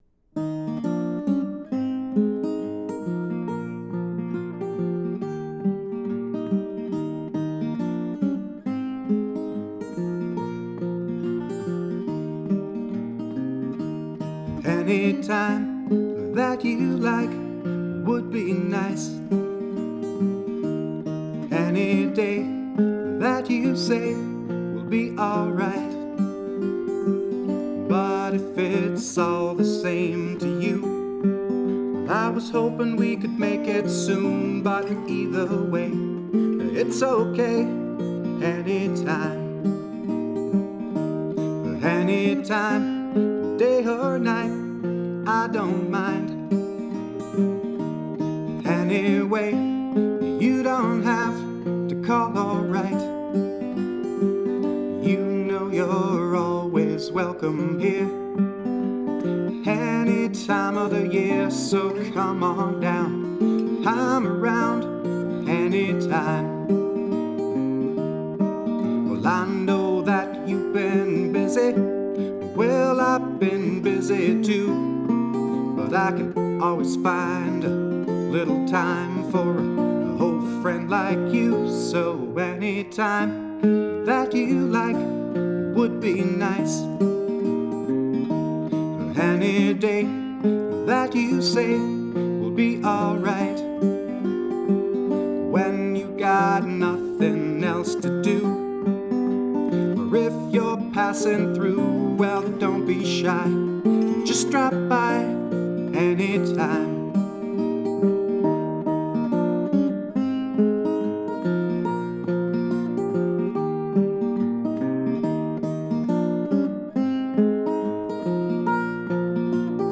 guitar & vocals